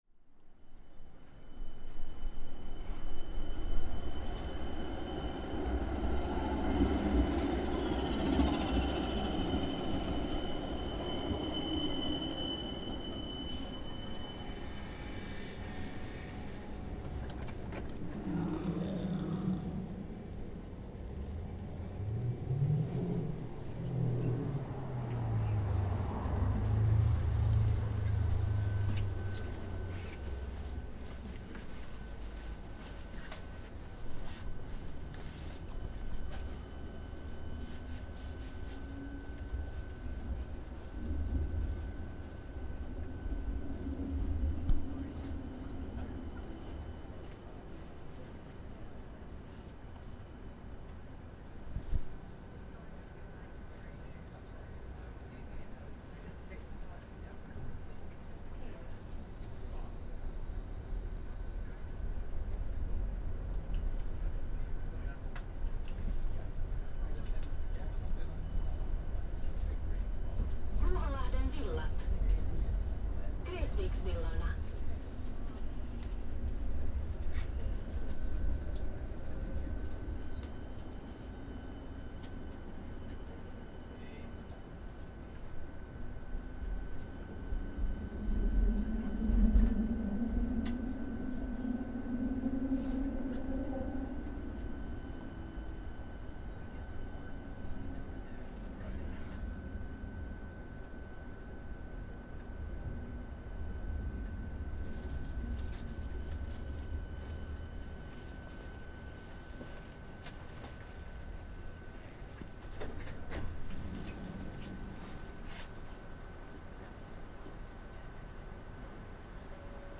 A tram ride from Kamppi in Helsinki city centre out to the ferry port terminal, with announcements, the drone of the tram, the sound of passengers, doors closing and so on.